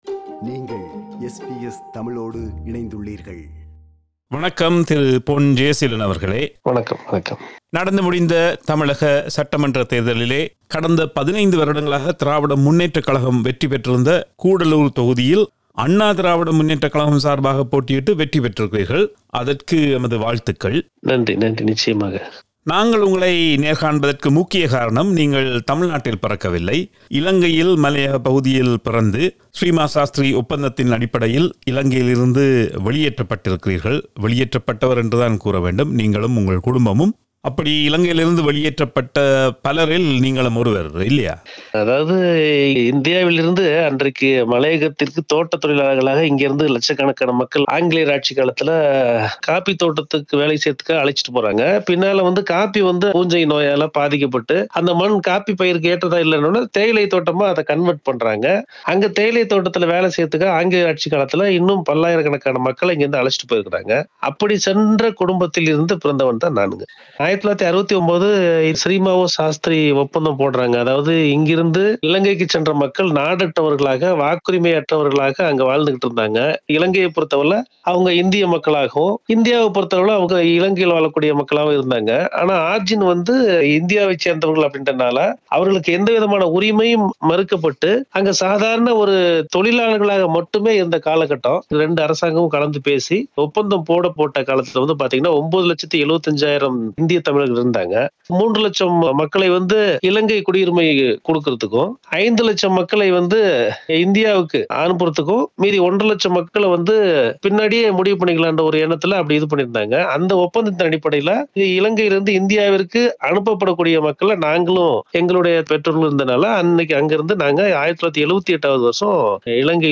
உரையாடுகிறார்